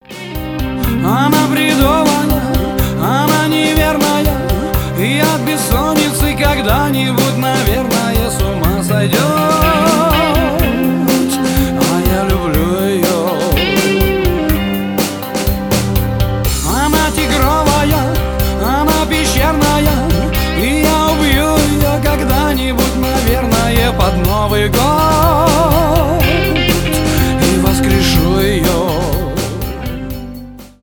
танцевальные , поп